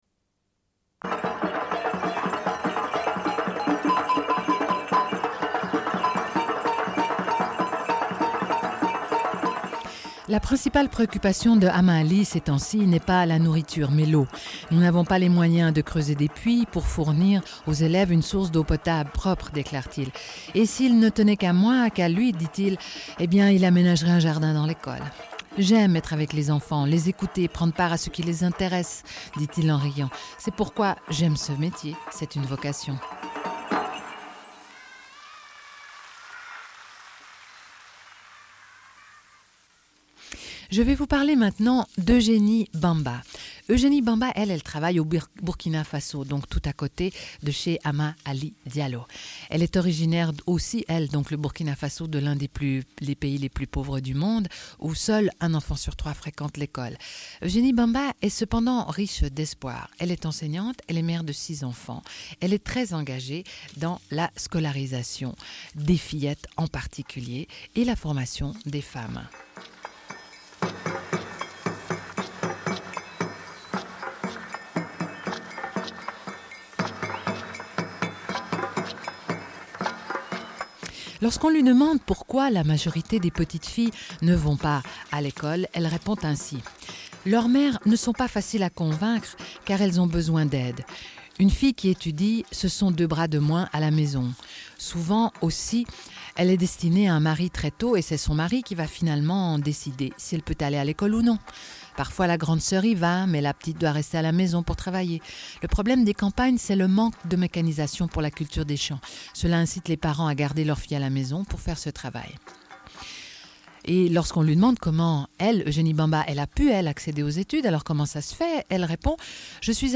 Diffusion d'un entretien réalisé à l'école Bait Fejar en Palestine, avec des enseignant·es en grève au moment de l'entretien (avril 1997).
Radio Enregistrement sonore